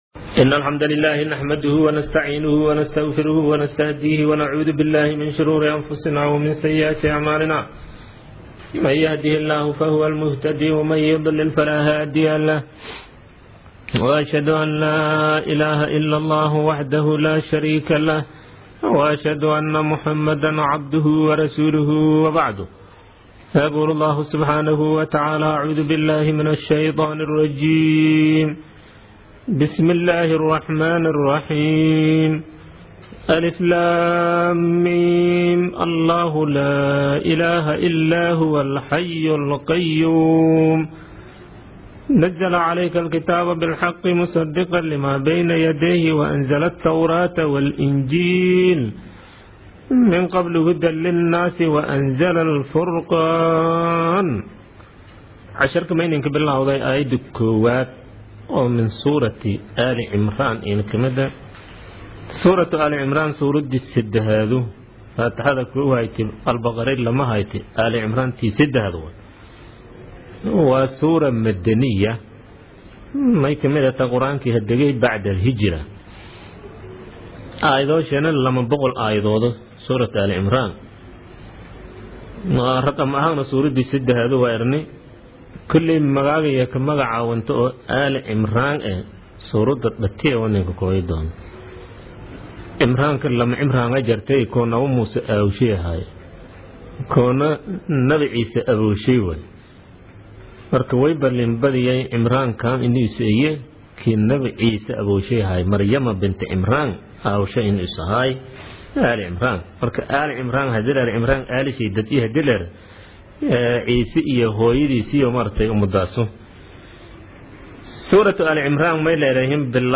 Casharka Tafsiirka Maay 39aad